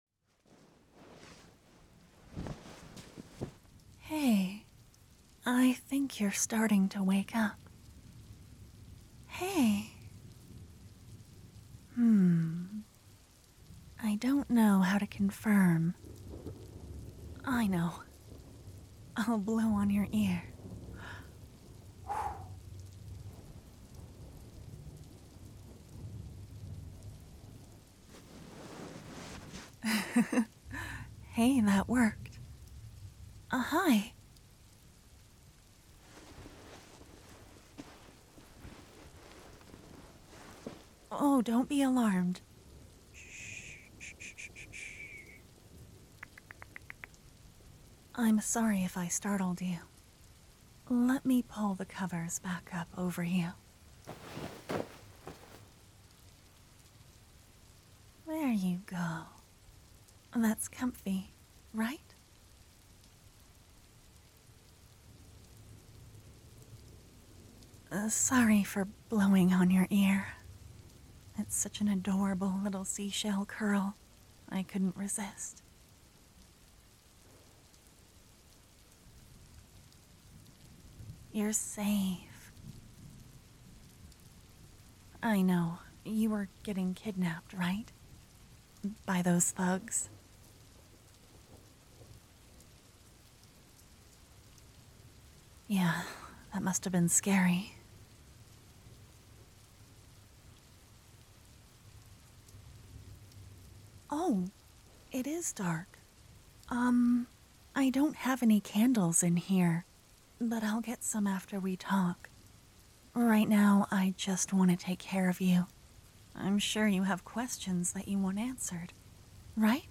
Pardon my experimentation, I did this one without any ambience / background music.
And the audio edits are very... restrained. I left in a few things I would normally fix, because I think it sounds a bit more human.